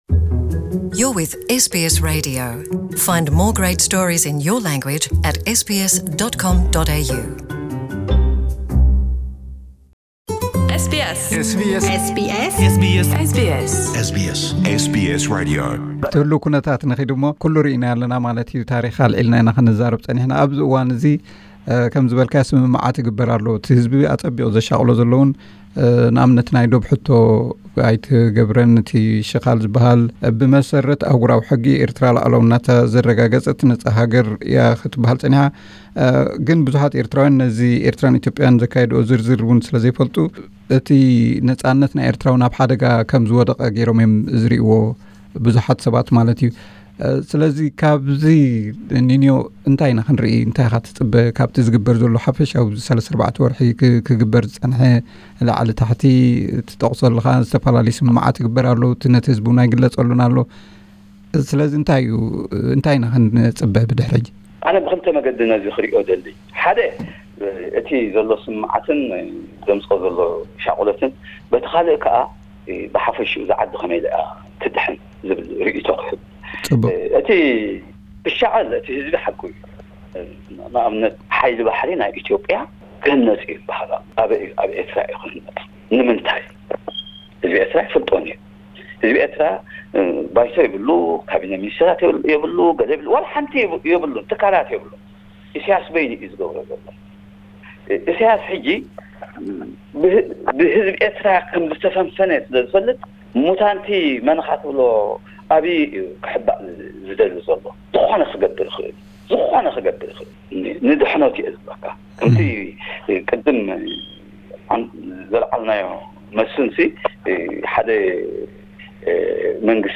ኣብዚ ናይ መወዳእታ ቃለ መሕትት ምስ ኣምባሳደር ኣድሓኖም መጻኢ ኩነታት ኤርትራ እንታይ ክመስል ከምዝኽእል ናይ ገዛእ ርእሱ ትንታነ ሂቡ ኣሎ። Interview with Amb. Adhanom Gebremariam PT3